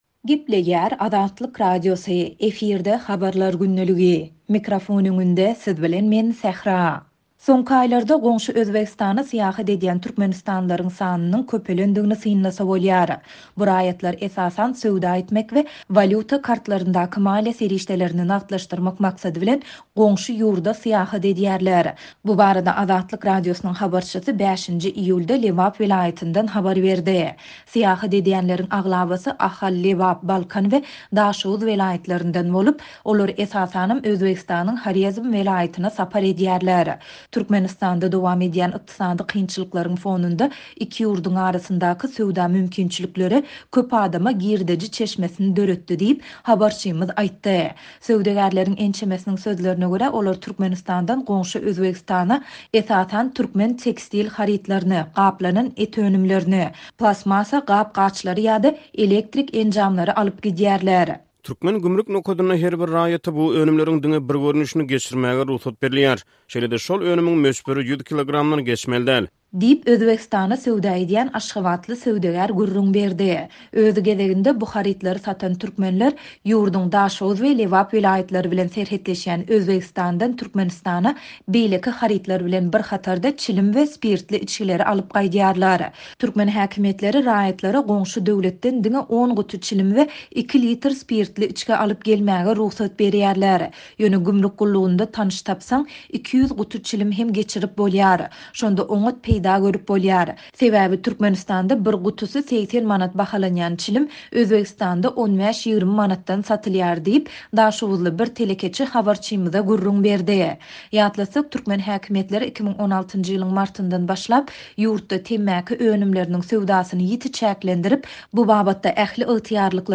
Bu barada Azatlyk Radiosynyň habarçysy 5-nji iýulda Lebap welaýatyndan habar berdi.